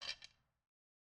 bow equip.wav